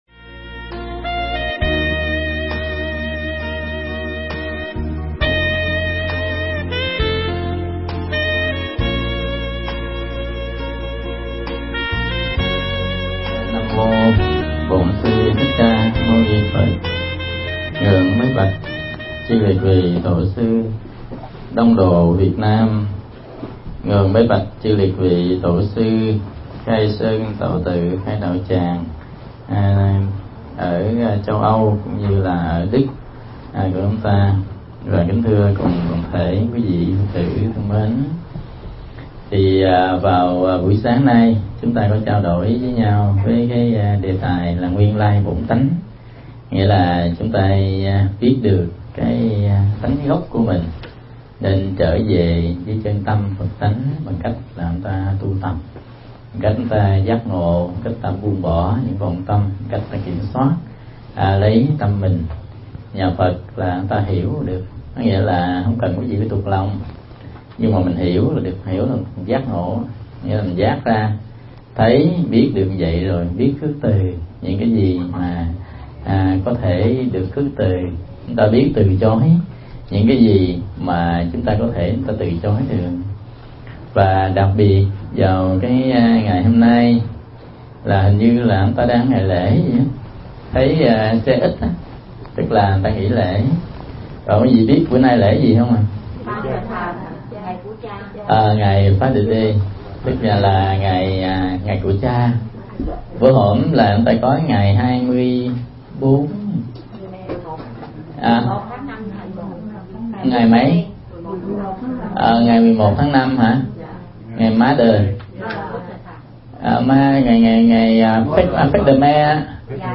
Pháp âm Nhớ Về Cha
Mp3 Thuyết Giảng Nhớ Về Cha